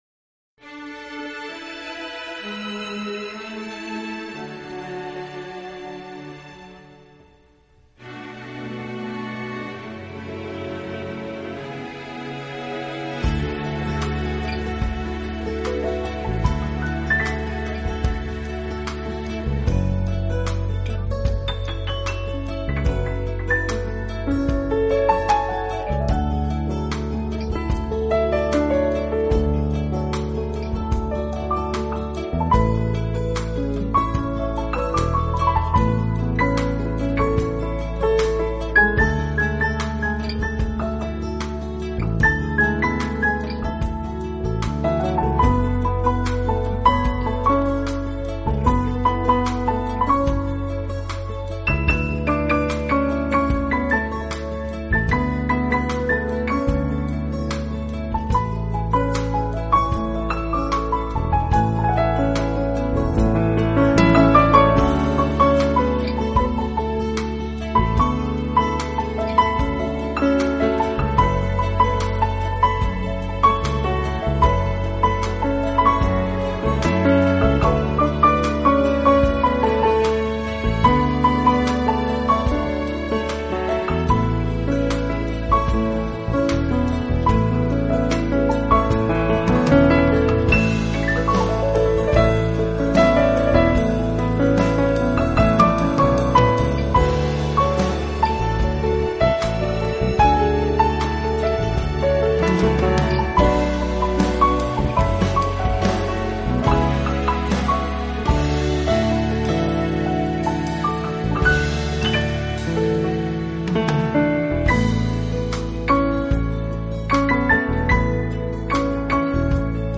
【钢琴纯乐】
音乐类型：钢琴
魔幻般的琴韵轻轻流泻，熟悉的流行金曲经巧妙改编，衬以萨克斯风，长笛及
吉他的伴奏，时而诉情，时而写意。